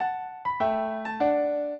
piano
minuet1-9.wav